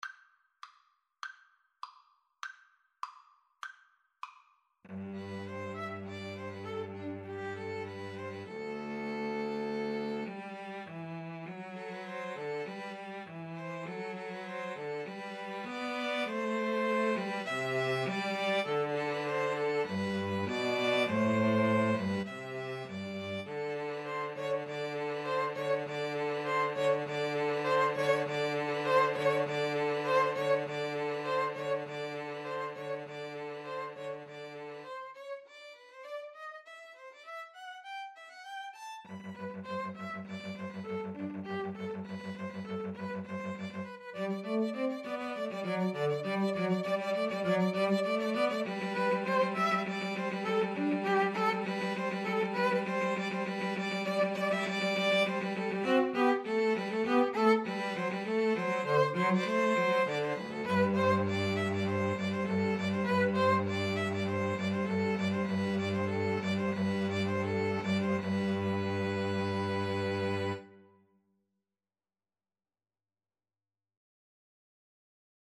Play (or use space bar on your keyboard) Pause Music Playalong - Player 1 Accompaniment Playalong - Player 3 Accompaniment reset tempo print settings full screen
G major (Sounding Pitch) (View more G major Music for 2-Violins-Cello )
2/4 (View more 2/4 Music)
Classical (View more Classical 2-Violins-Cello Music)